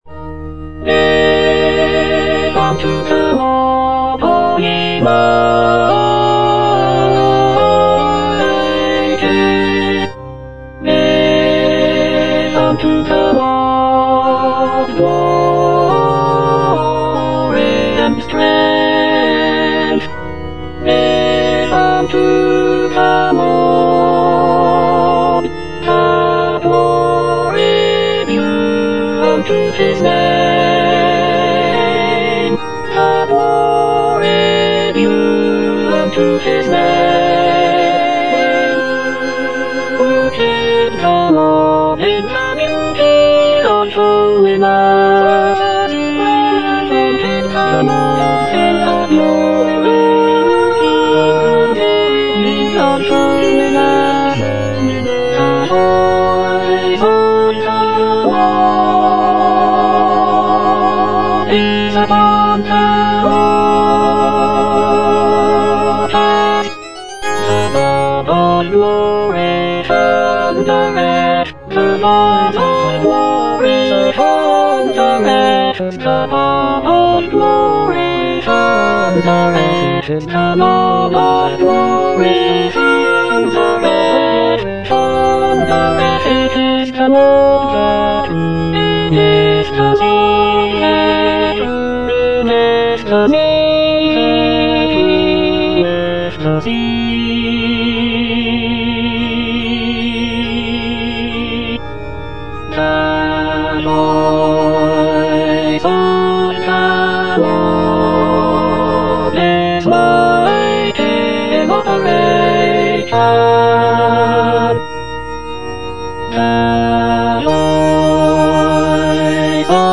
E. ELGAR - GIVE UNTO THE LORD Alto II (Emphasised voice and other voices) Ads stop: auto-stop